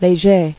[Lé·ger]